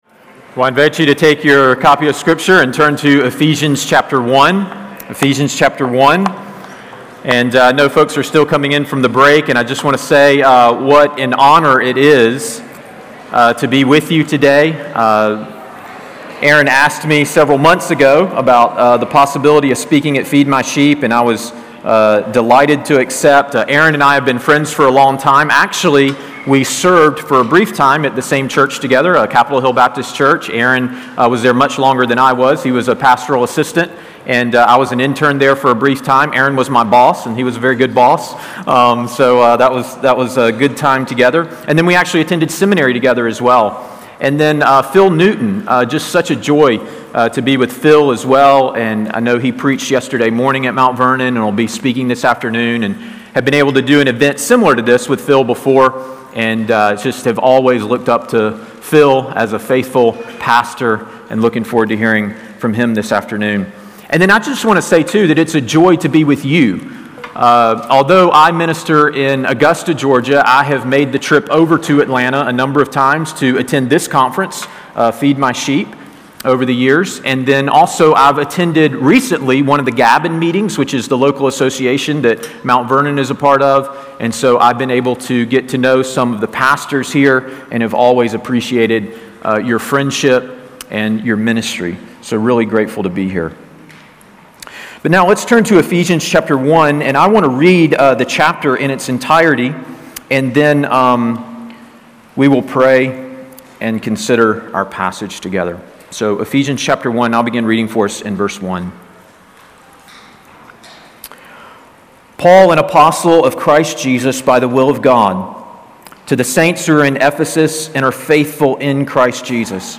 Audio recorded at Feed My Sheep 2023.